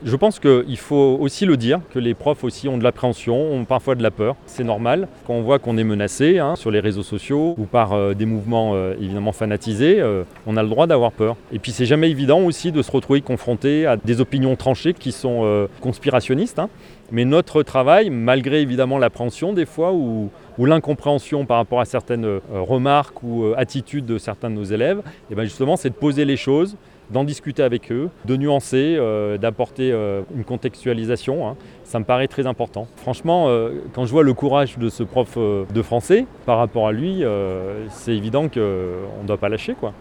Fabien Géry, adjoint à la culture à la mairie d’Annecy et professeur d’histoire-géographie au lycée Charles Baudelaire ne minimise pas la peur du corps enseignant, mais rappelle l’objectif premier de ce métier.
ITC Fabien Géry 4-Rassemblement hommage Dominique Bernard.wav